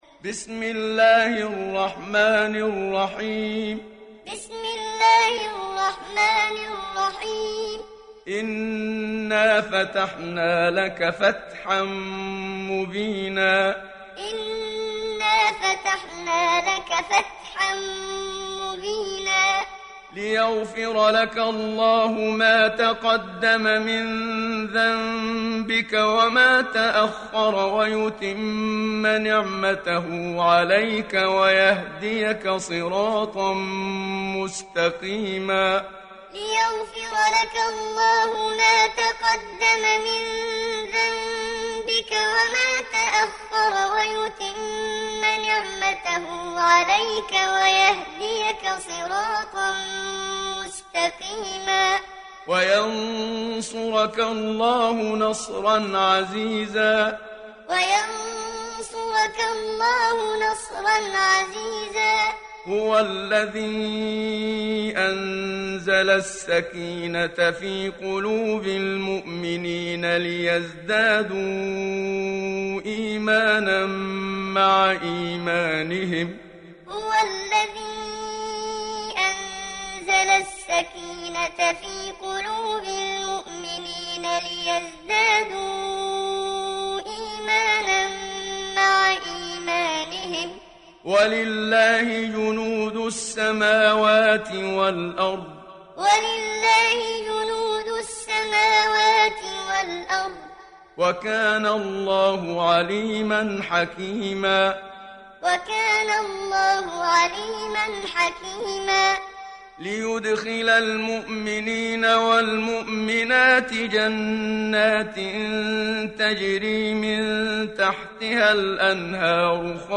Surat Al Fath Download mp3 Muhammad Siddiq Minshawi Muallim Riwayat Hafs dari Asim, Download Quran dan mendengarkan mp3 tautan langsung penuh